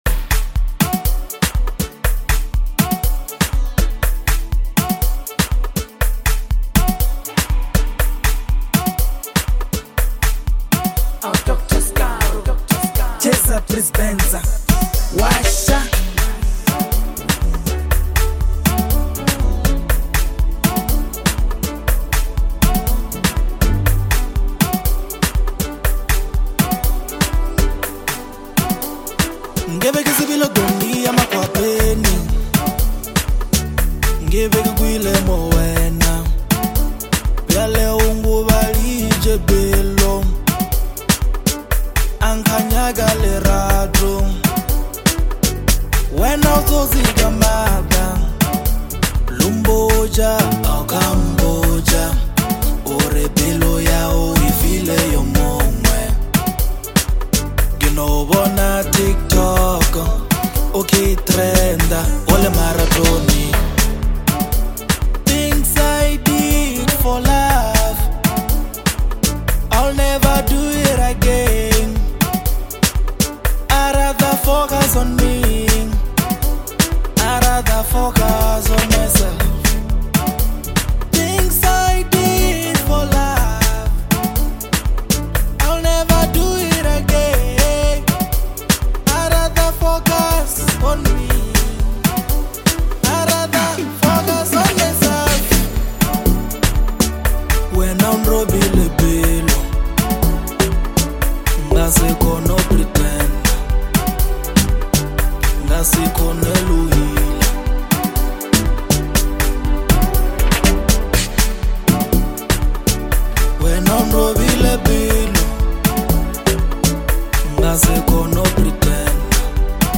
The deeply emotional track